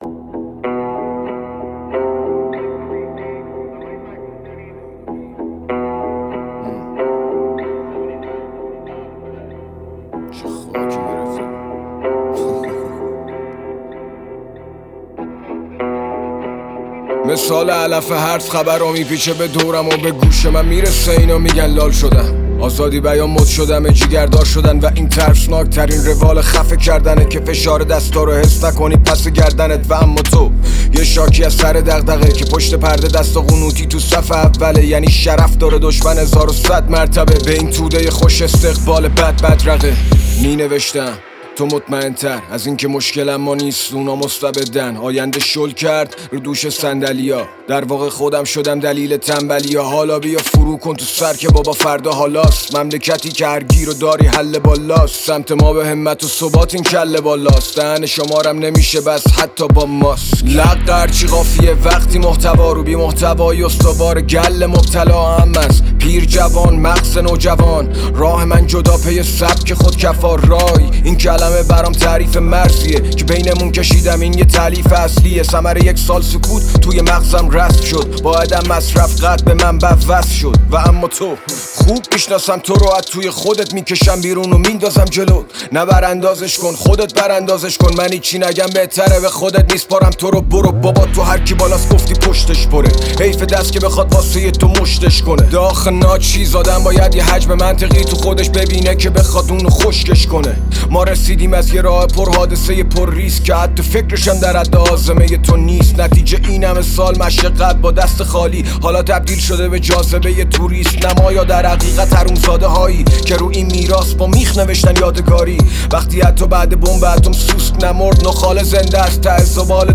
• دسته آهنگ رپ